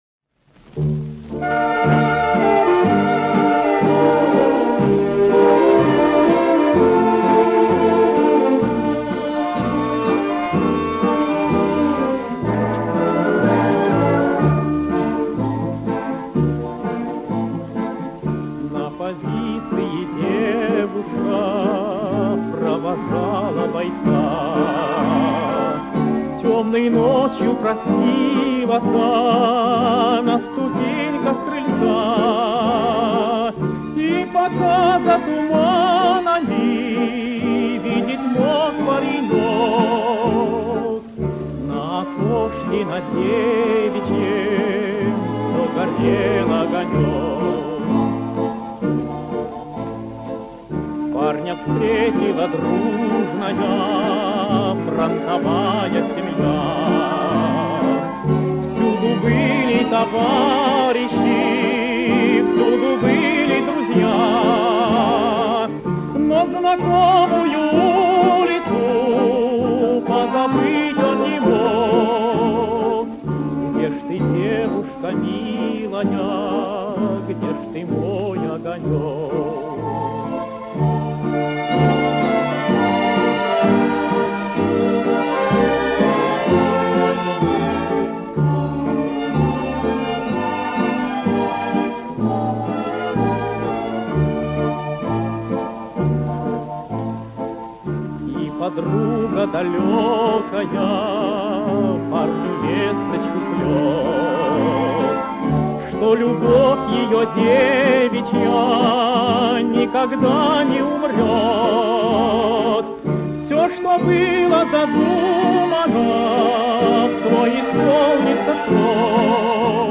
Каталожная категория: Тенор с оркестром |
Жанр: Песня
Вид аккомпанемента: Оркестр
Место записи: Москва |
Скорость оцифровки: 78 об/мин